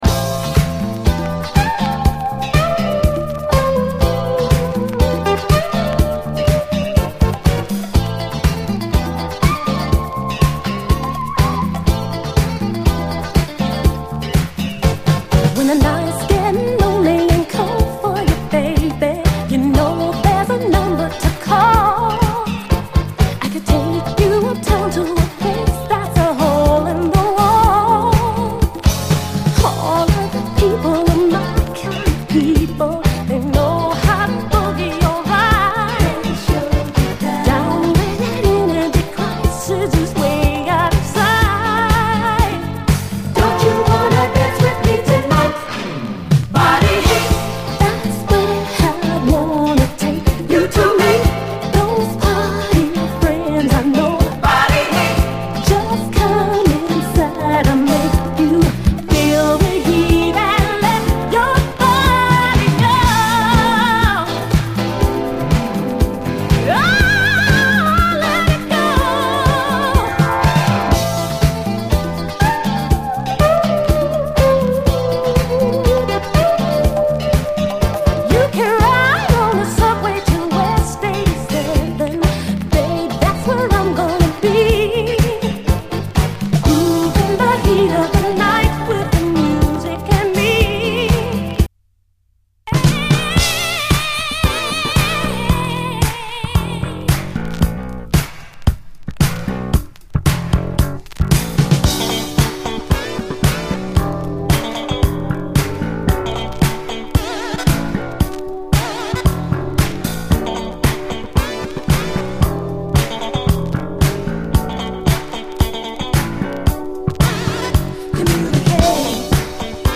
SOUL, 70's～ SOUL, DISCO
隠れた80’Sブギー・ダンサー！
隠れた80’Sモダン・ブギー！アーバンでエレクトリックなシンセ使い！